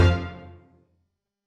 Jump Scare Sting
A sudden, sharp orchestral sting designed to shock and startle with maximum impact
jump-scare-sting.mp3